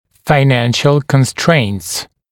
[faɪ’nænʃ(ə)l kən’streɪnts][фай’нэнш(э)л кэн’стрэйнтс]финансовые ограничения